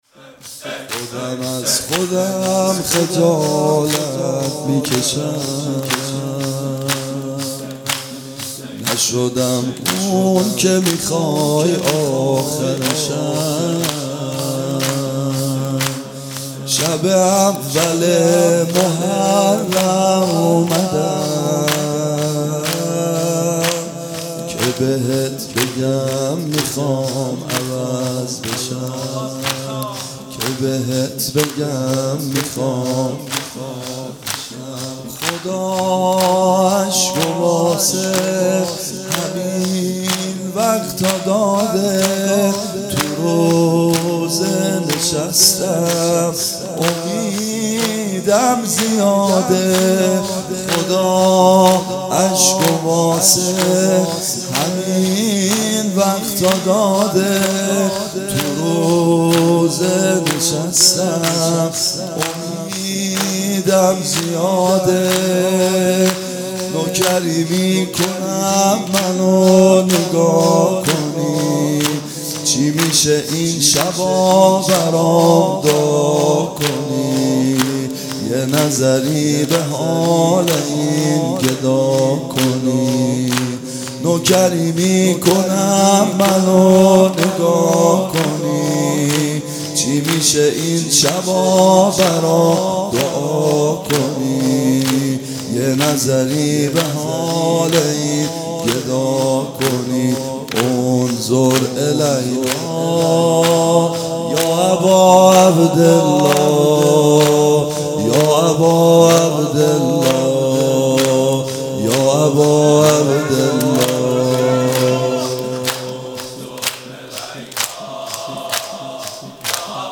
شب اول محرم97